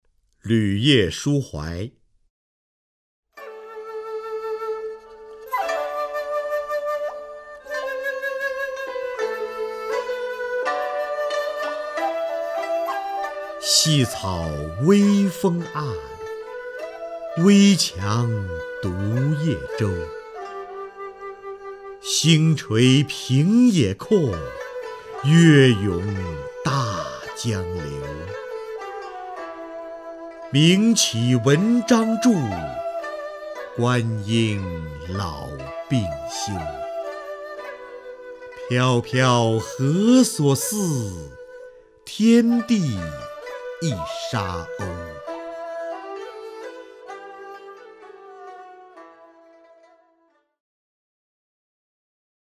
瞿弦和朗诵：《旅夜书怀》(（唐）杜甫) （唐）杜甫 名家朗诵欣赏瞿弦和 语文PLUS